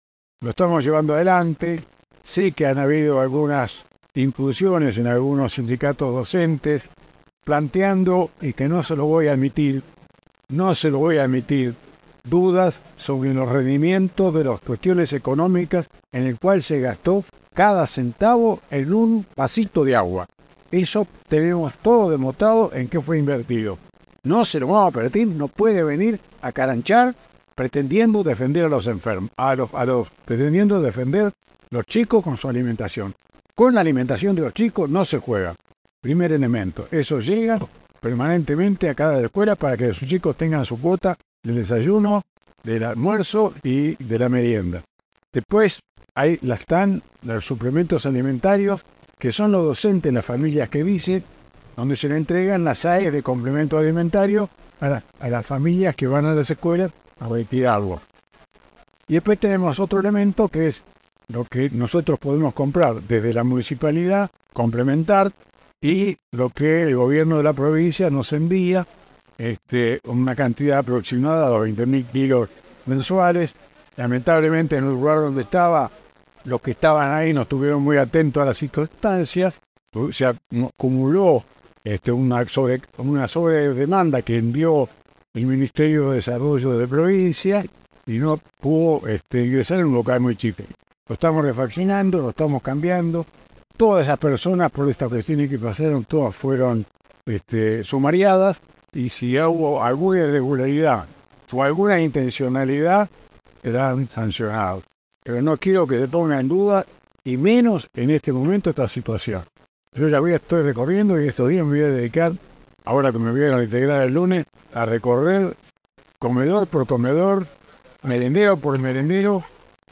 El Intendente de La Costa, Juan de Jesús, se pronunció en rueda de prensa tras el homenaje al General San Martín, defendiendo el Servicio Alimentario Escolar (SAE) y criticando las denuncias realizadas por algunos gremios sobre la calidad del suministro alimentario para los estudiantes.
AUDIO DE Intendente Juan de Jesús :